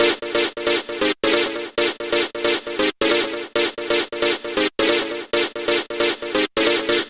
Stab 135-BPM A#.wav